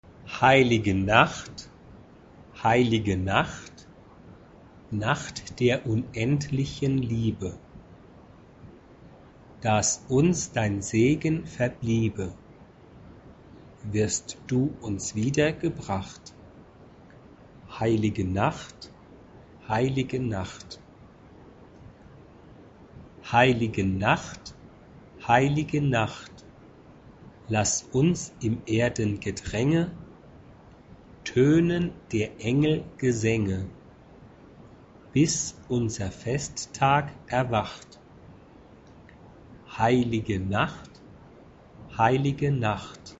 SAB (3 voices mixed) ; Full score.
Christmas carol. Choral setting.
Mood of the piece: calm
Instruments: Keyboard (optional)
Tonality: B flat major